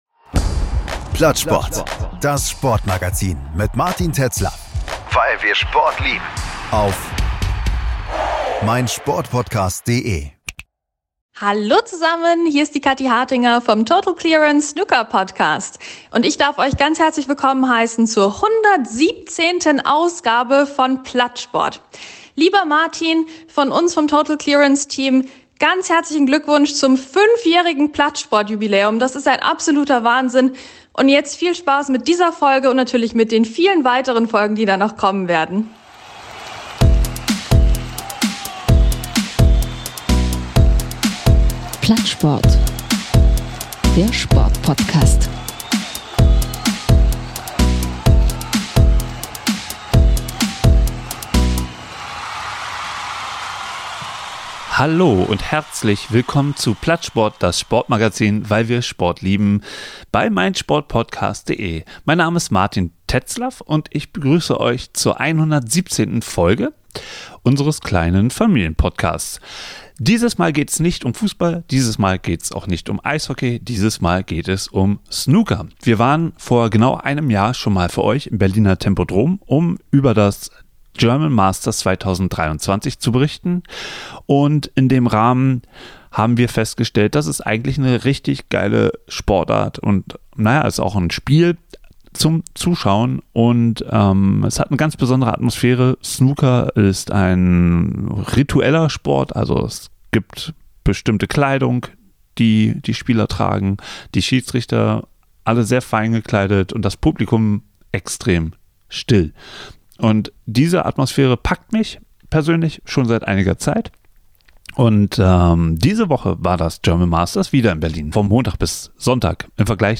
Vergangene Woche von Montag bis Sonntag war die World Snooker Tour (WST) zu Gast im Berliner Tempodrom. Wir waren auch da und haben ein paar spannende Gespräche geführt.